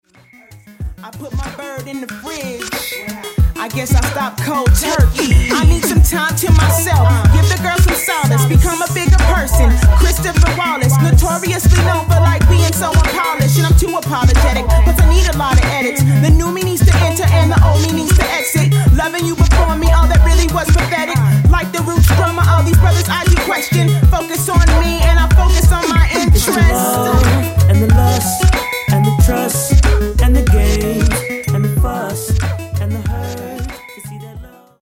girl-boy duo
feel-good hip hop tracks